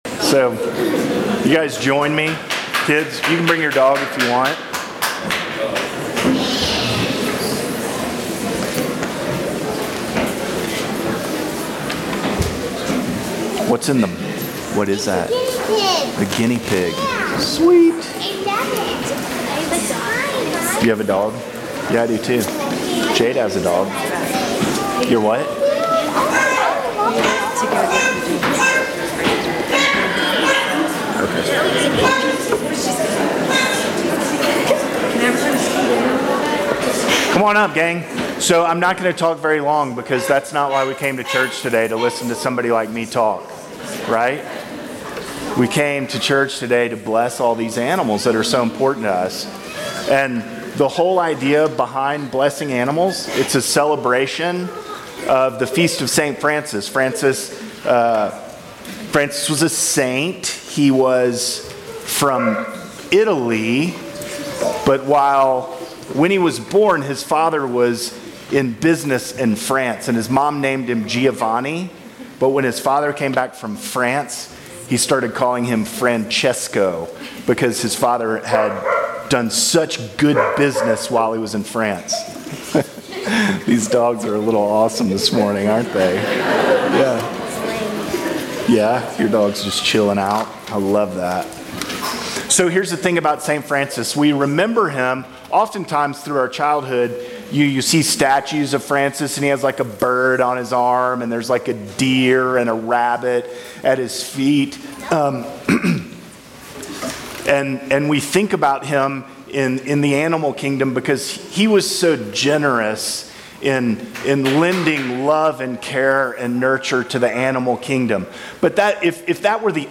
Sermons from St. John's Episcopal Church